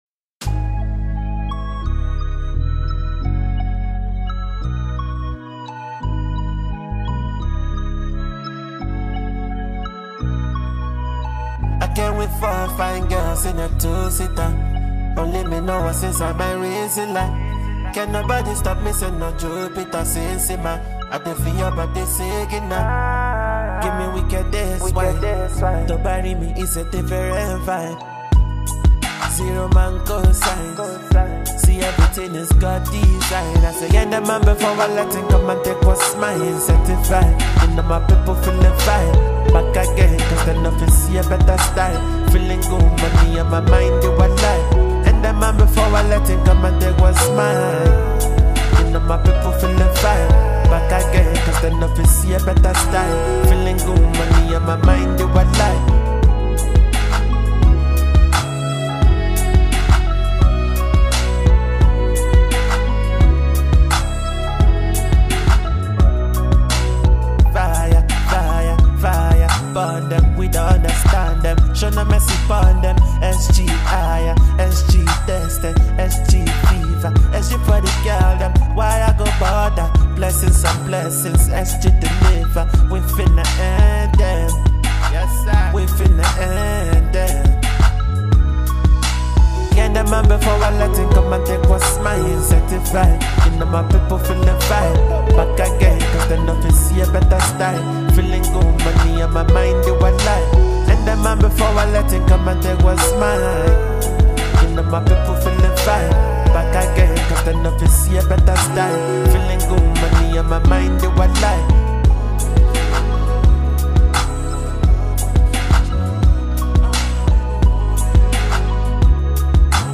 Nigerian singer and songwriter
soft percussions, moody synths
melodic voice gliding effortlessly across every verse